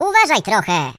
share/hedgewars/Data/Sounds/voices/Default_pl/Watchit.ogg